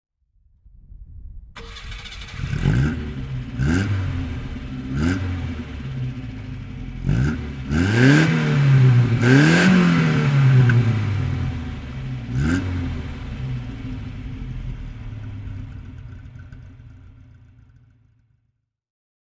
Sunbeam 1500 GT (1973) - Starten und Leerlauf